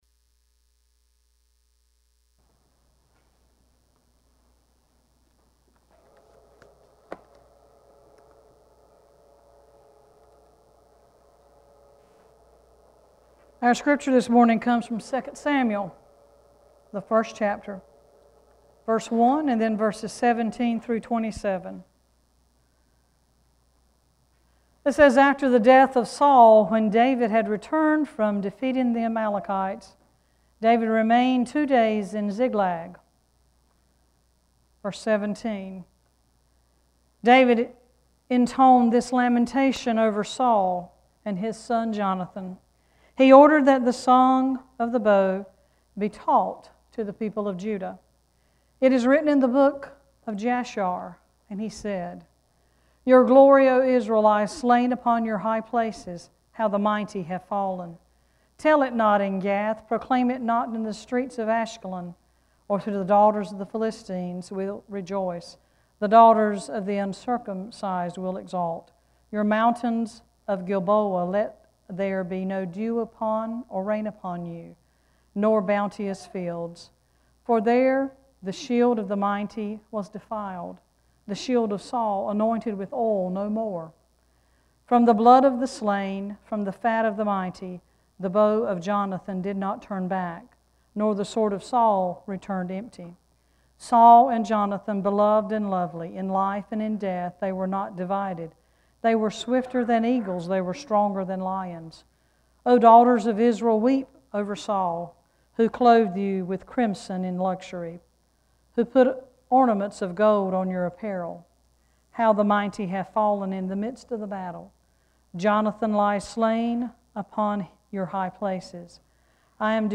6-5-scripture.mp3